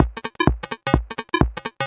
Electrohouse Loop 128 BPM (10).wav